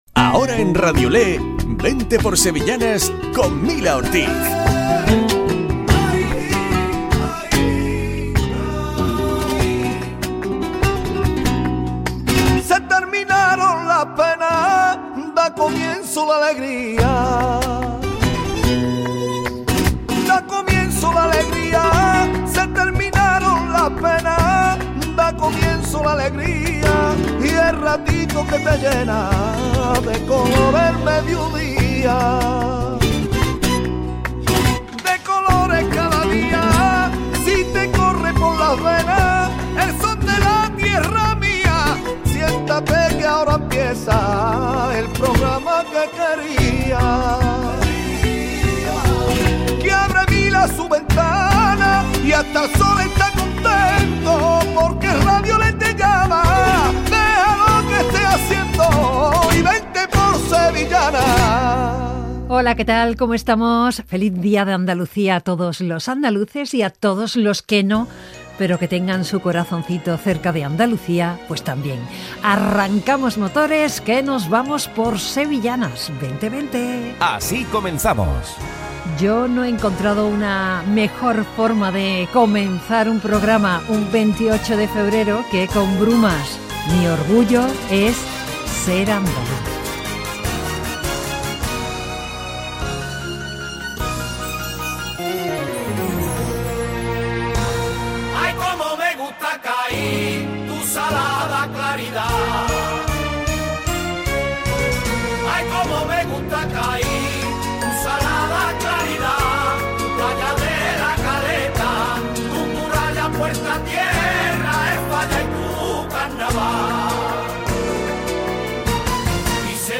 Programa dedicado a las sevillanas.